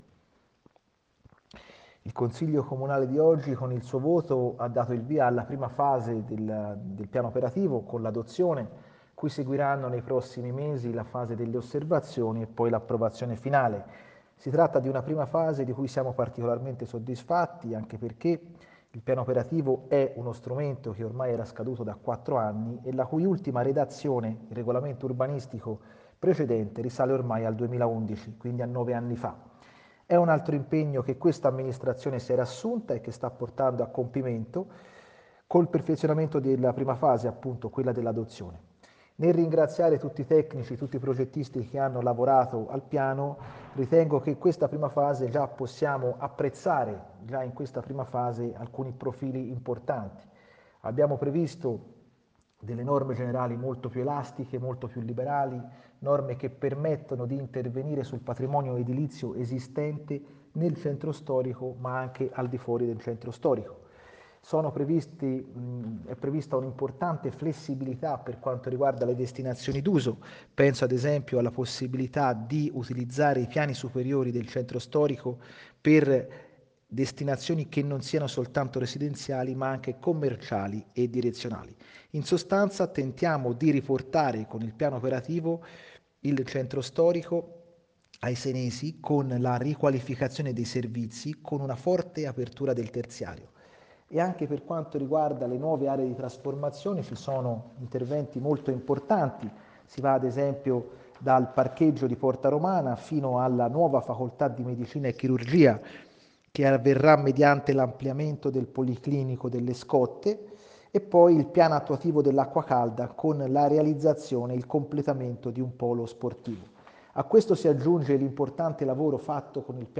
Ascolta il commento dell’assessore Michelotti